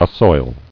[as·soil]